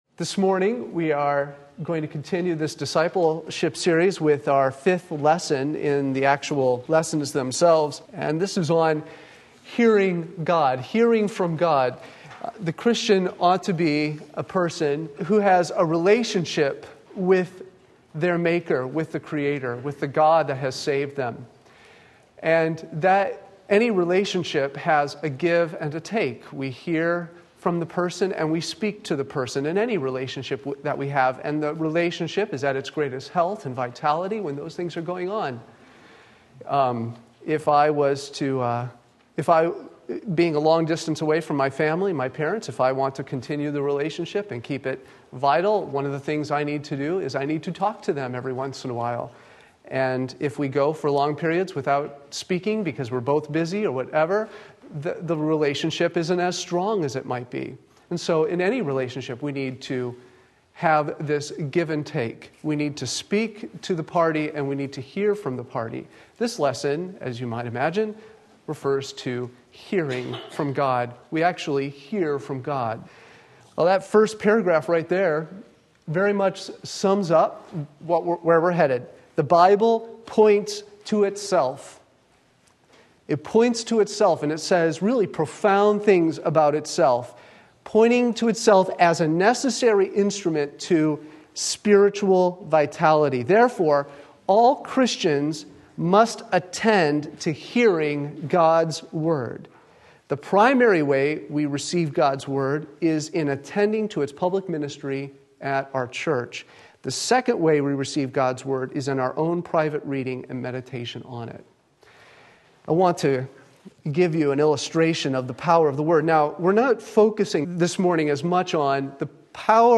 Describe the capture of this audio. Bible Preaching and Reading 1 Thessalonians 2:13 Sunday School